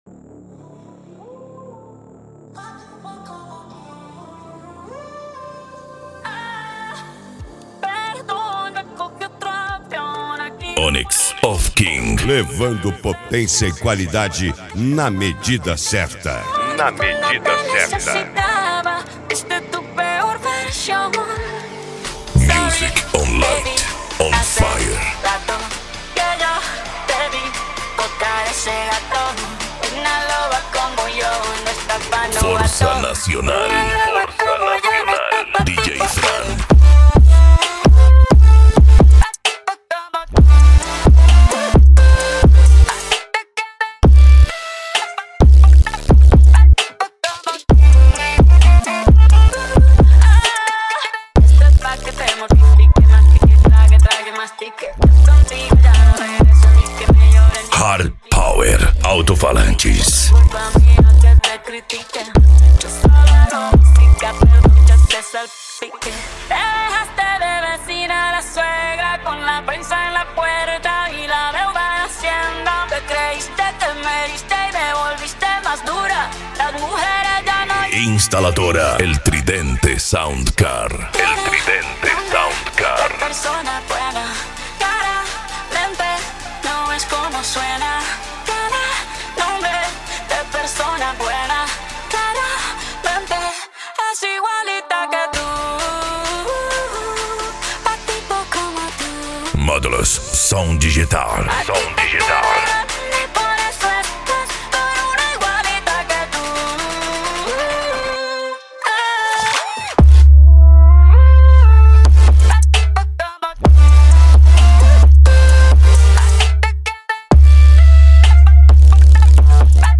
Bass
Remix